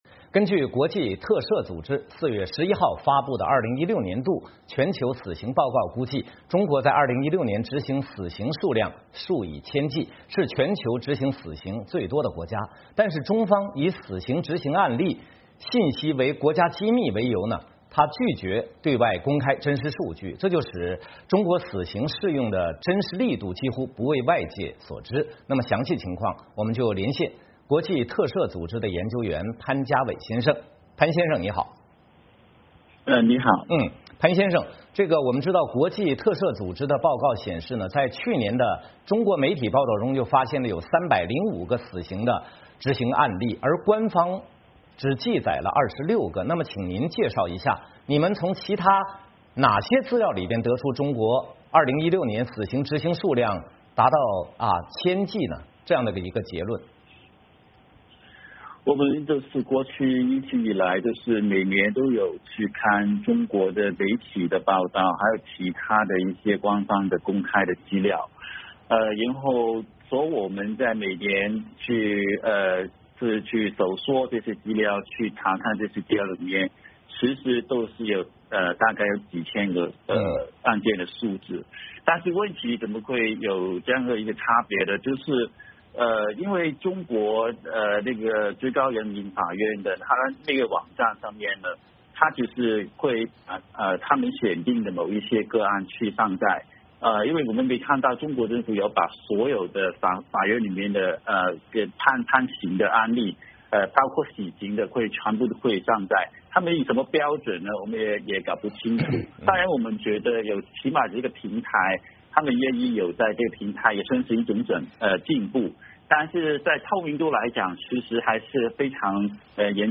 VOA连线：国际特赦：中国是2016执行死刑最多的国家